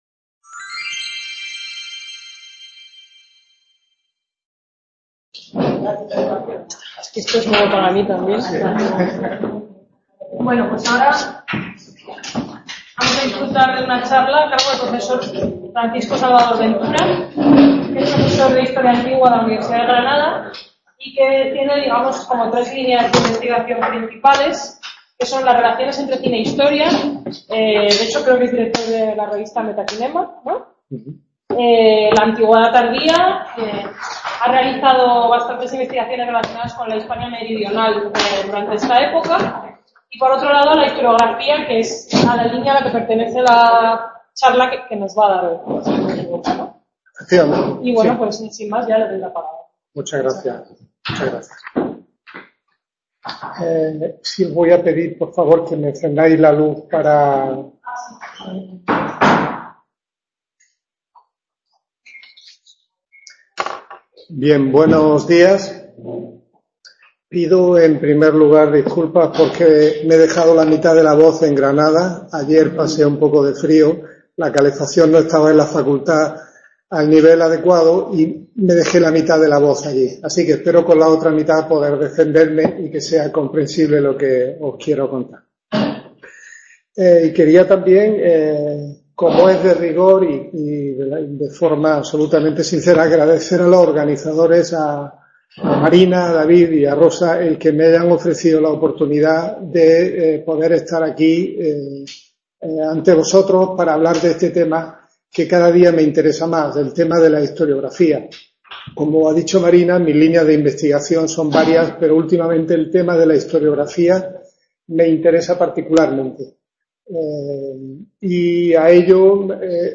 II Jornada de Actualización Científica sobre Antigüedad Tardía (2ª parte) Jornada de divulgación y actualización científica del grupo de investigación internacional sobre antigüedad tardía en el marco de la Asociación Barbaricum, entre la UCM y la UNED.